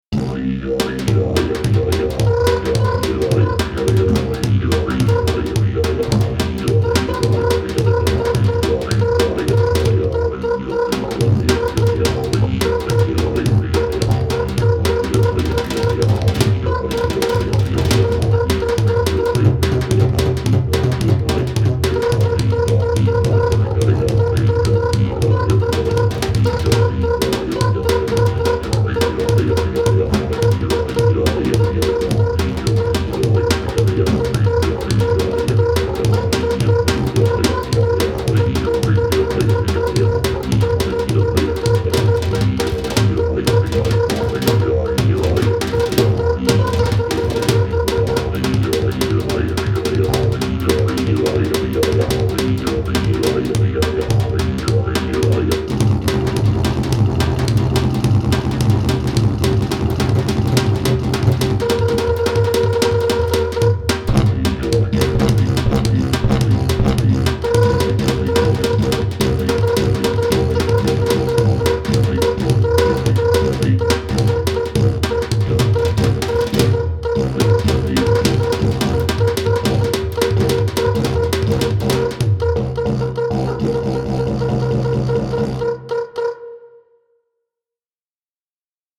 Genre : Experimental